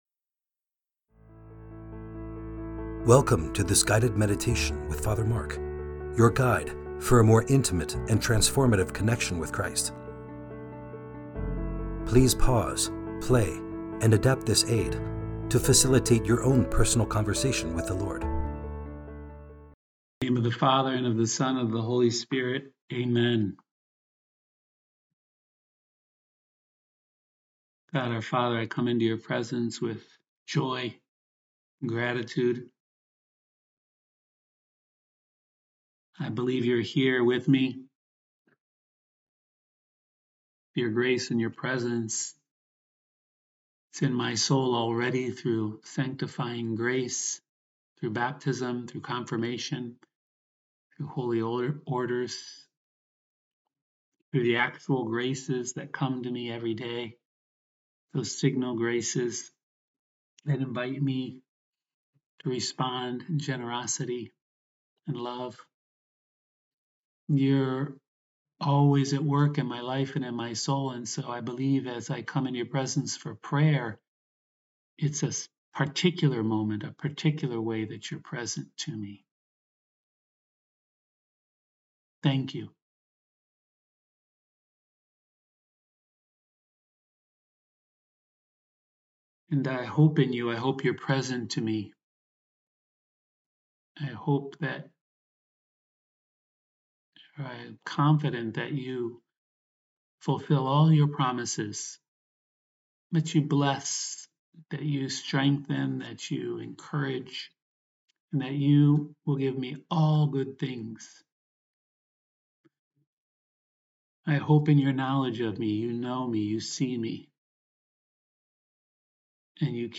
Guided Meditation